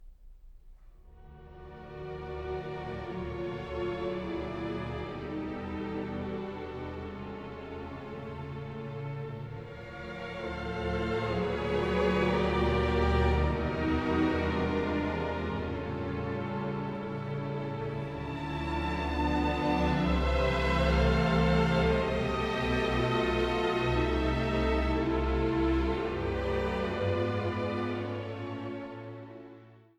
Sehr langsam – Sehr lebhaft